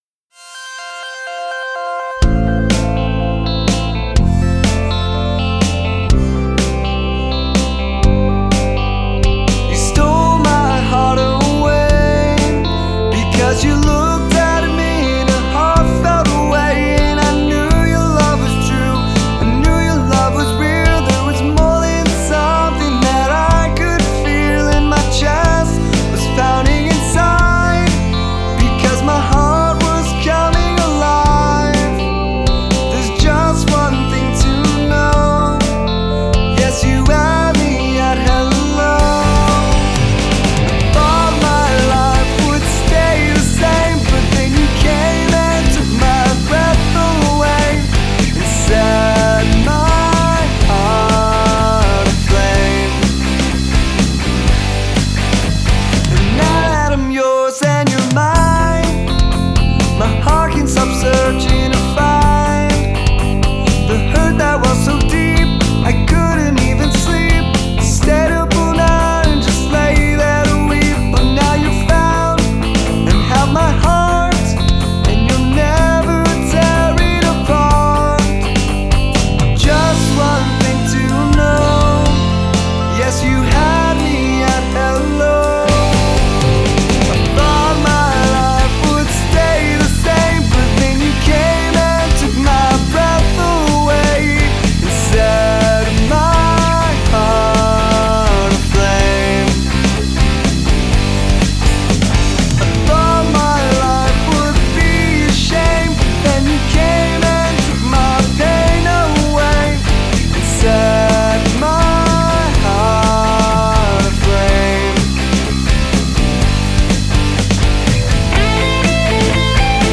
Recorded, Mixed and Mastered
Additional Orchestration and Arranging
Vocals, Keyboard Programming
Guitars, Bass, Keyboard Programming
Drums